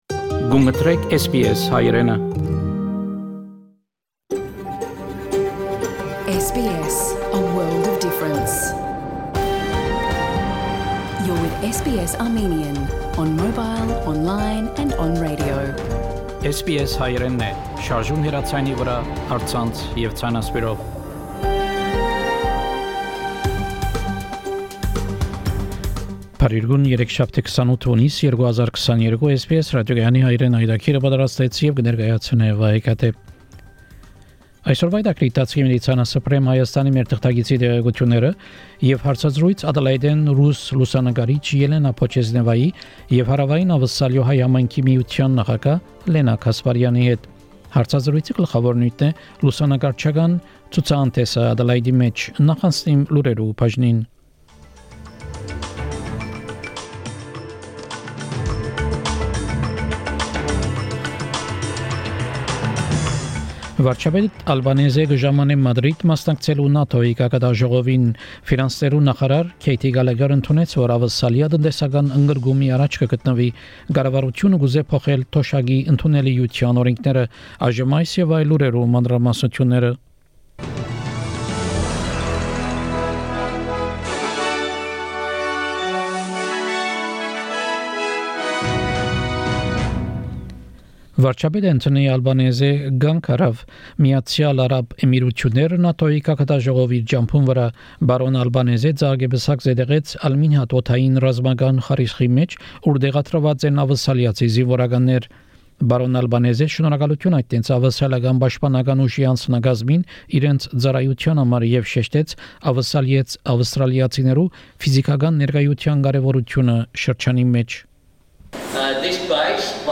SBS Armenian news bulletin – 28 June 2022
SBS Armenian news bulletin from 28 June 2022 program.